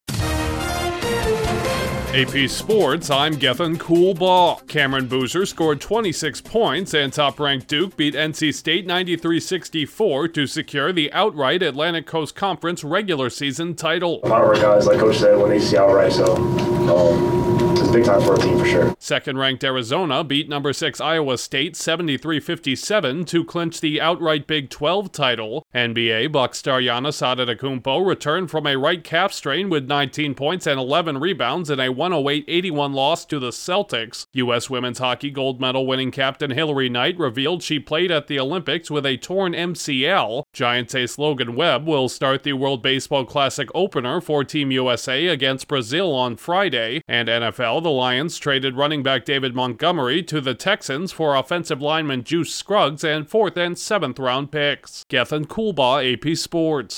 Duke claims ACC title to headline men’s college hoops action, a two-time NBA MVP returns from a 15-game injury absence, an American Olympic hockey star reveals she was playing hurt, a two-time MLB All-Star gets Team USA’s WBC opening nod and a two-time 1,000-yard rusher is traded in the NFL. Correspondent